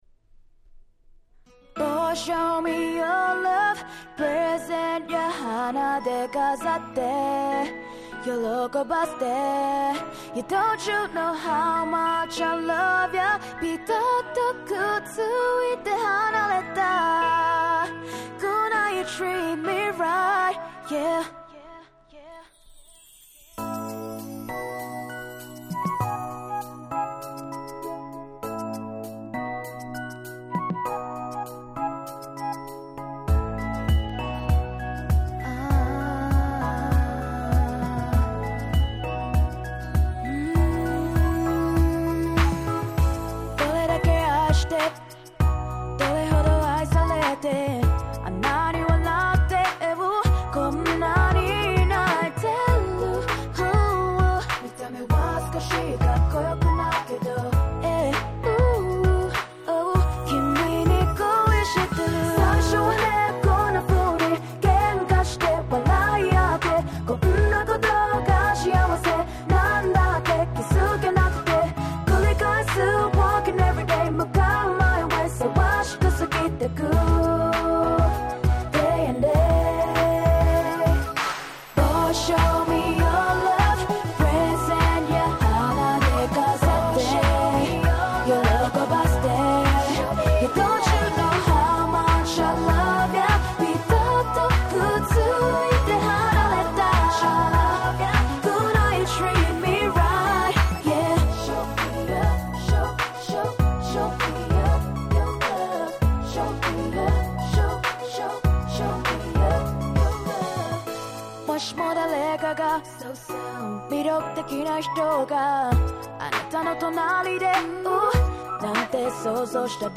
10' Nice Japanese R&B !!
切ないLove Songで凄く良いです！！